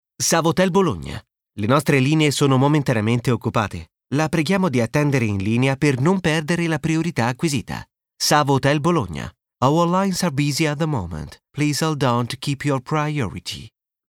IT MD IVR 01 IVR/Phone systems Male Italian